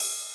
ride1.ogg